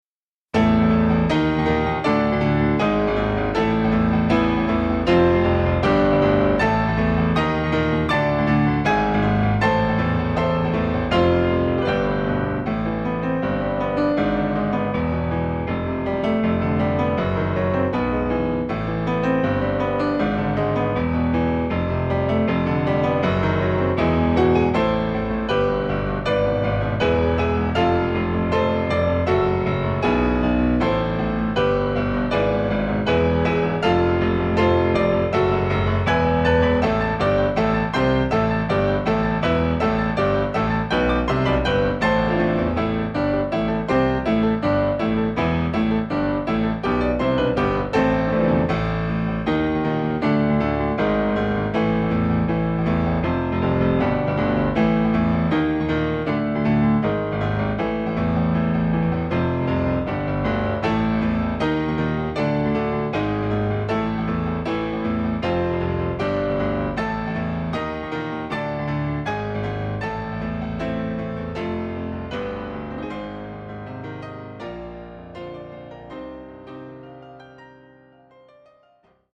※주의! 링크는 크로스페이드로 직접 연결됨